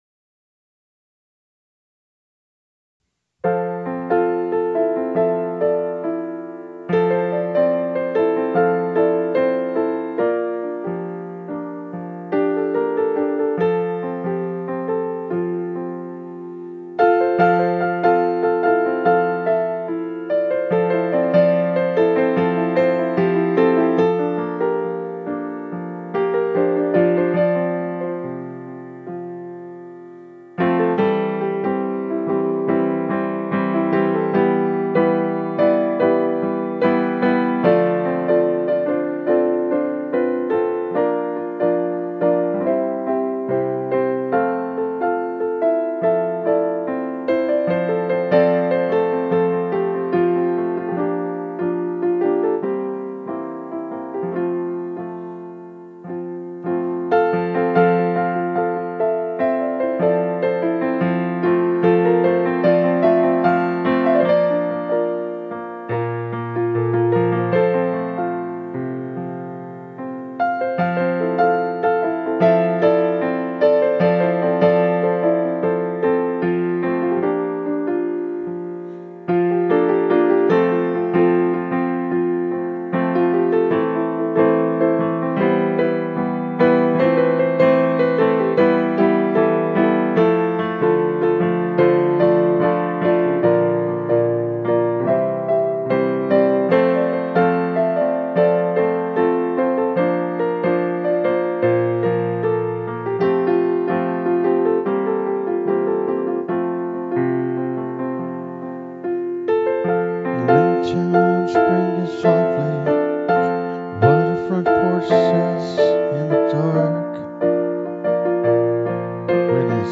Sat down Sunday afternoon, sunny day, quiet, cracked the door to let in the air...I live in a little 20 X 40 garage apartment above a first floor storage area, live with people who were friends of a friend and now the friend is not so much and they are better friends than before..anyway, it's…